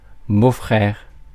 Ääntäminen
Ääntäminen France: IPA: [bo.fʁɛʁ] Haettu sana löytyi näillä lähdekielillä: ranska Käännös Ääninäyte Substantiivit 1. brother-in-law US 2. stepbrother 3. co-brother-in-law Suku: m .